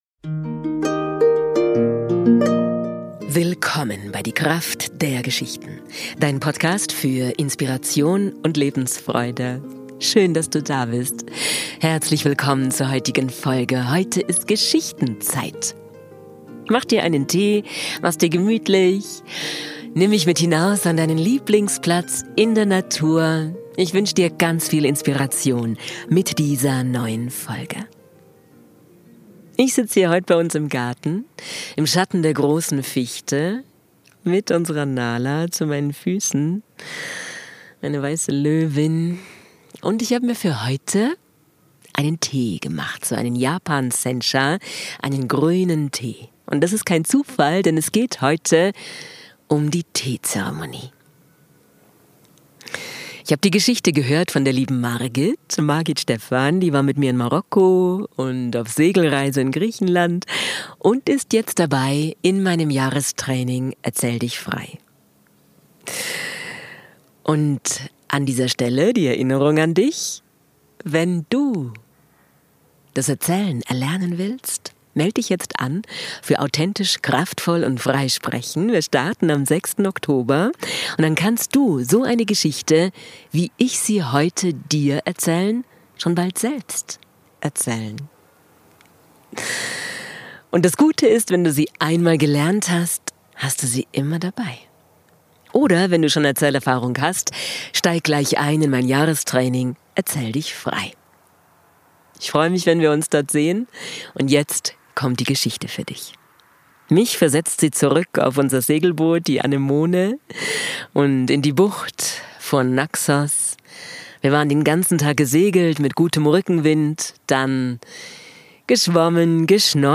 Ich erzähle Dir eine Geschichte über die Kunst der Teetrinkens. Sen no Rikyū, der Meister der Teezeremonie wird von einem Samurai zum Duell herausgefordert.